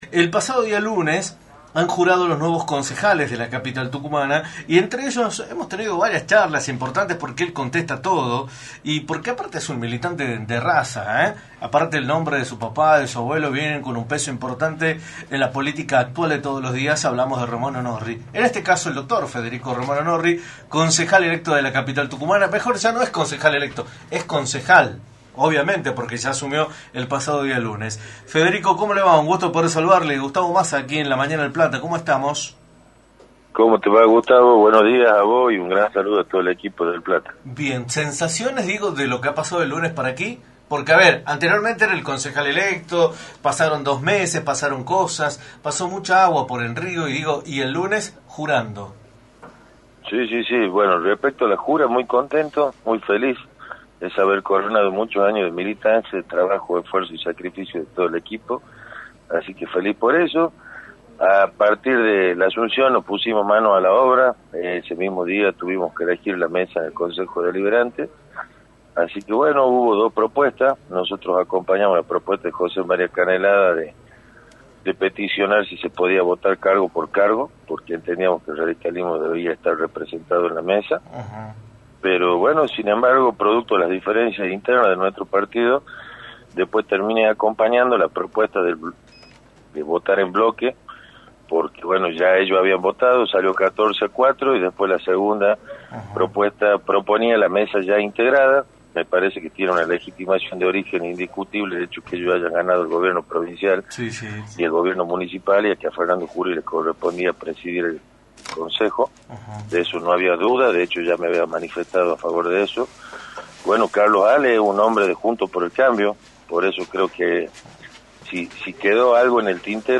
Federico Romano Norri, Concejal de San Miguel de Tucumán, analizó en Radio del Plata Tucumán, por la 93.9, la situación política de la provincia, luego de que el lunes los Concejales electos jurarán ante la Constitución.